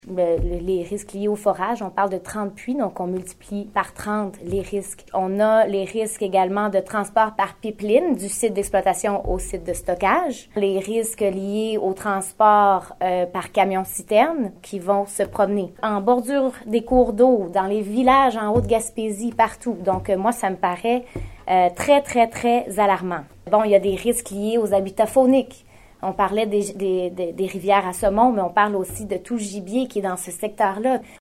En conférence de presse hier, Méganne Perry Mélançon a justifié sa position ferme notamment en raison des risques liés aux forages et au mode de transport du pétrole brut: